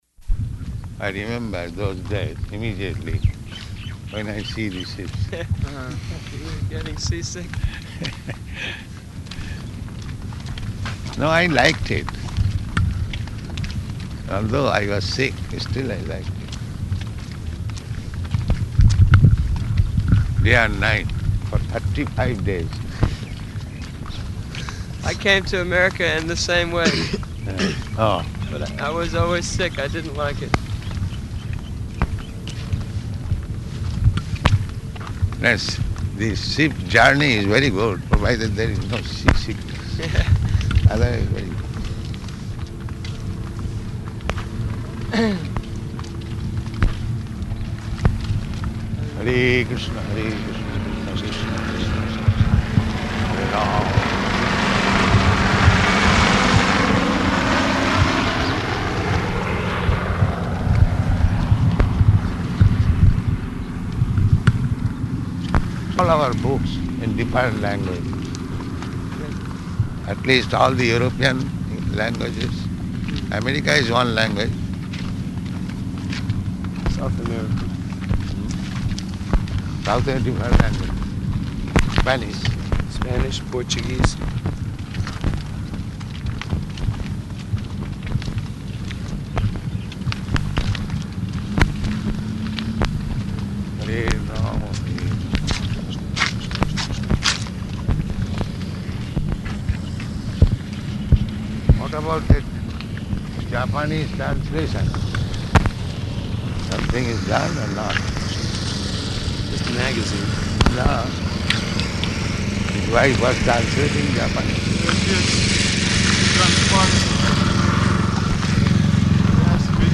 Morning Walk --:-- --:-- Type: Walk Dated: February 4th 1975 Location: Honolulu Audio file: 750204MW.HON.mp3 Prabhupāda: I remember those days immediately when I see the sea.